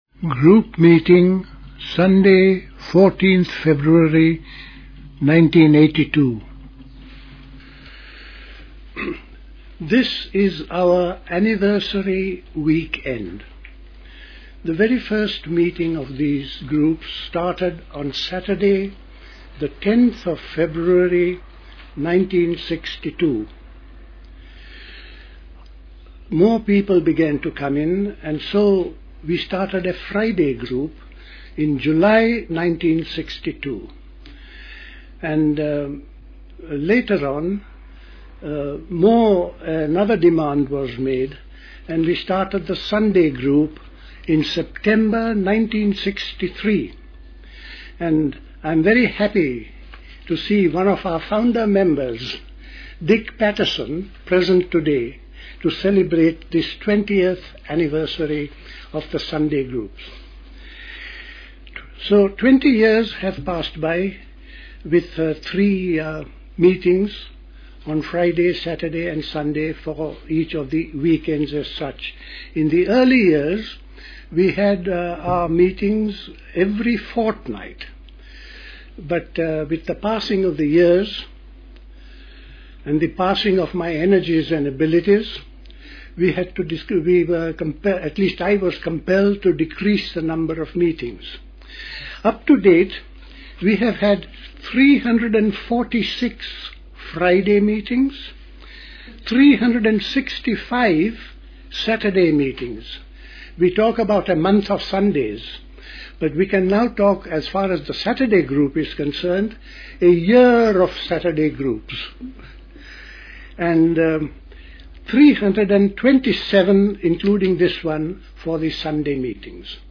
A talk given